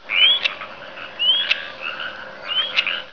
El Bailarín también llamado Milano bailarín (Elanus leucurus)
bailarin.wav